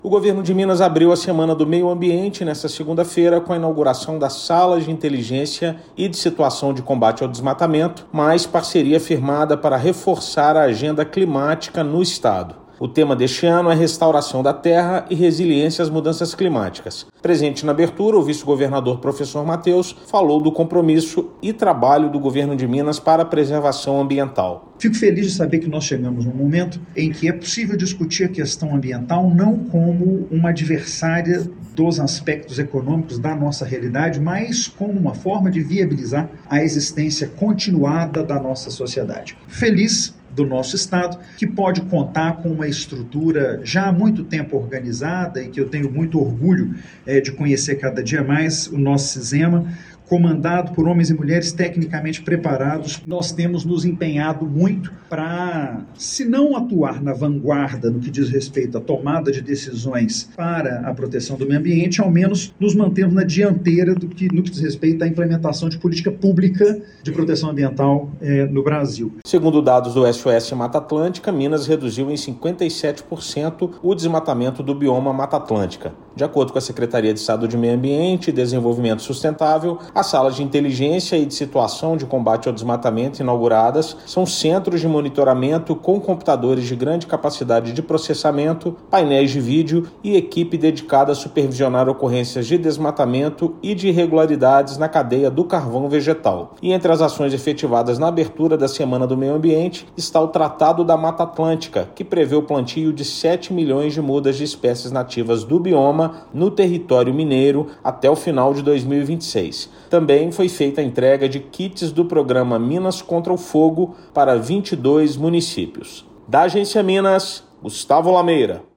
Vice-governador de Minas, Professor Mateus destacou o compromisso e o trabalho feito pelo Estado na proteção do meio ambiente. Ouça matéria de rádio.